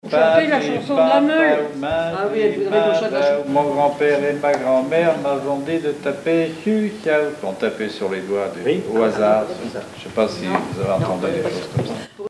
formulette enfantine : amusette
Chansons, formulettes enfantines
Pièce musicale inédite